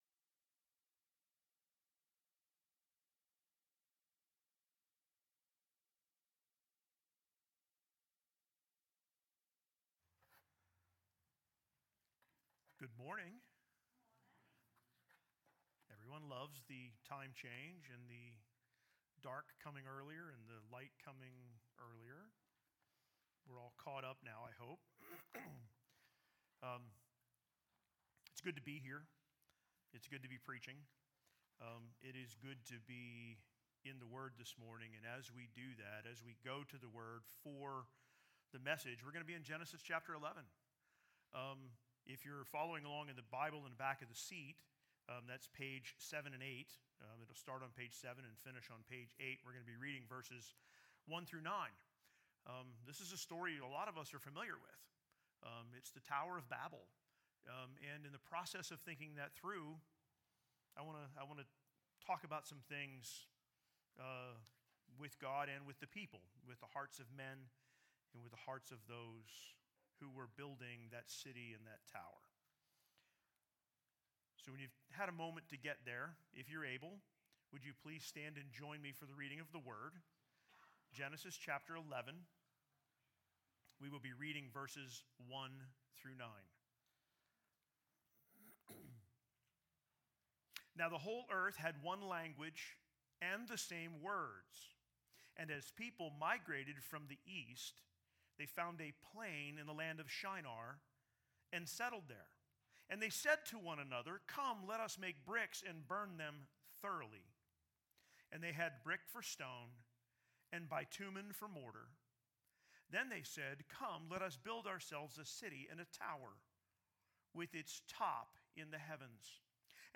SERMONS | Sunbury City Church